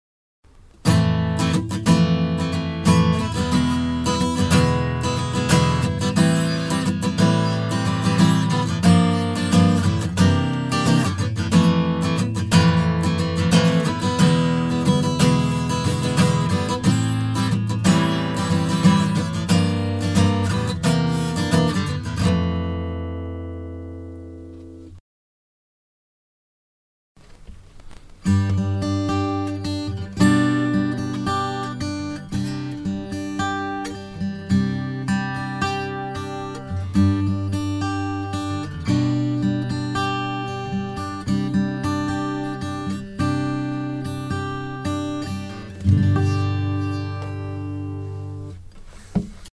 試聴用のオーディオは、前半にストローク、後半にアルペジオが入っています。
TTMは上向きにも下向きにもコンプレッションを行い、小音量を引き上げ、大音量を抑えるという効果が得られるスタイルで…これはアコギに適しているとは言えない気もしますね。
聴いてみるとダイナミクスが一定過ぎていかがなものかという気になりました。
特にアルペジオからは表情が消えてしまって残念な感じがしますね。これは音色に合っていないということがわかりました。